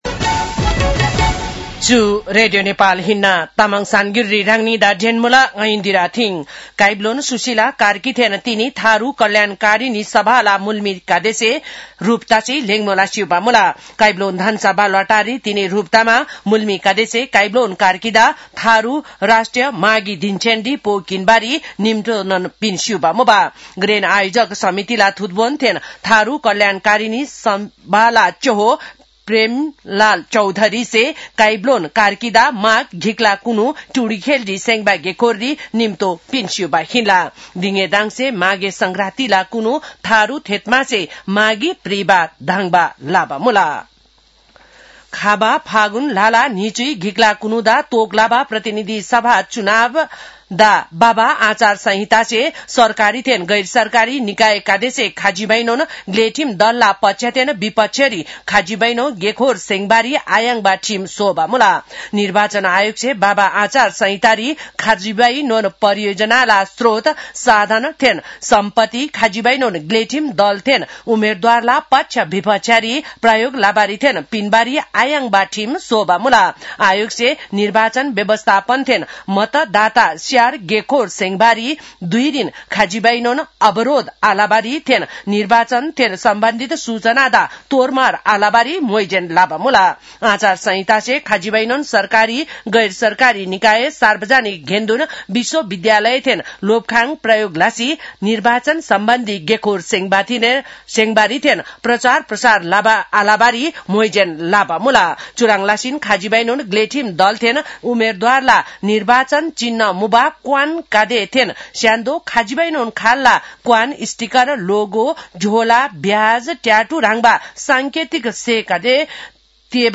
तामाङ भाषाको समाचार : २० पुष , २०८२
Tamang-news-9-20.mp3